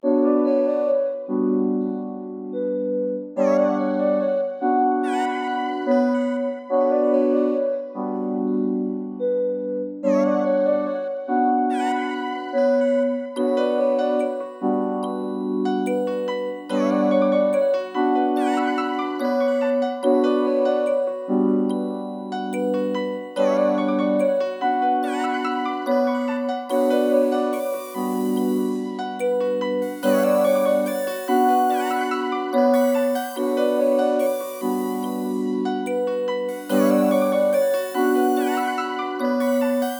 Melody (Down Bad).wav